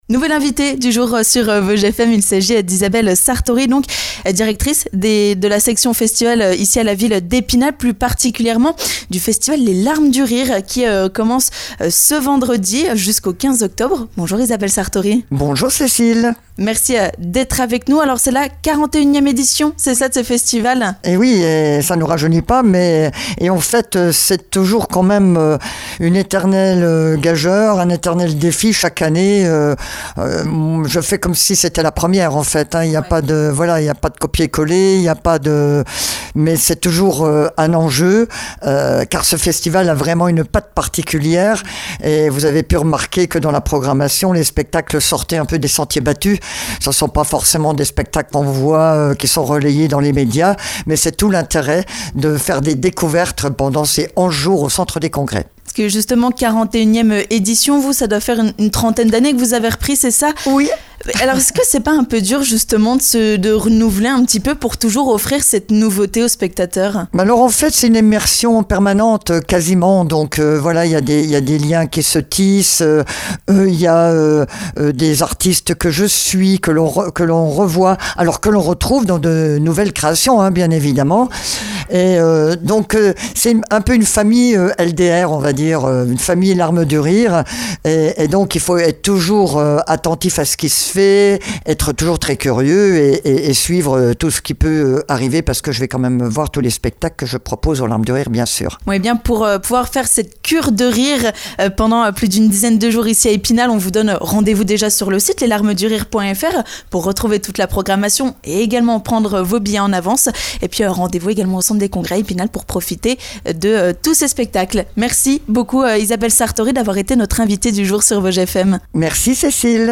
est notre invitée du jour sur Vosges FM ! Elle nous explique comment elle se renouvelle chaque année pour faire découvrir de nouvelles choses aux spectateurs.